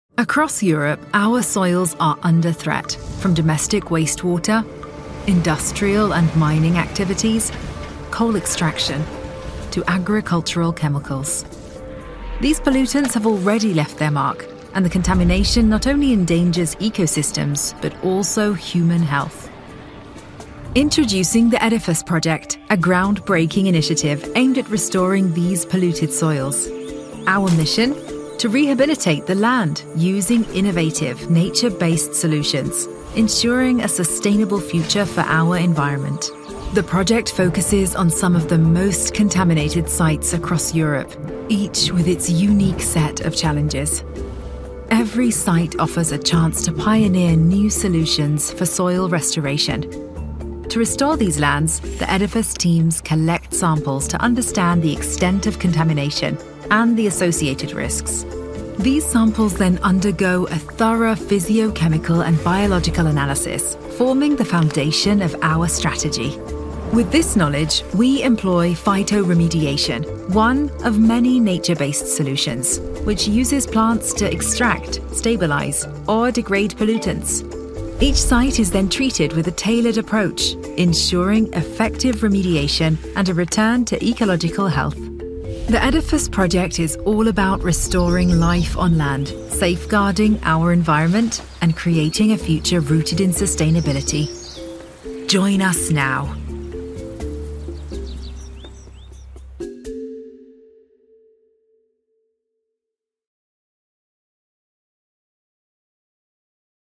E-learning
I have a professional home recording studio and have lent my voice to a wide range of high-profile projects.
LA Booth, Rode, Audient id4
DeepLow
TrustworthyAuthoritativeConfidentFriendlyExperiencedReliable